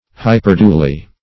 hyperduly - definition of hyperduly - synonyms, pronunciation, spelling from Free Dictionary Search Result for " hyperduly" : The Collaborative International Dictionary of English v.0.48: Hyperduly \Hy"per*du`ly\, n. Hyperdulia.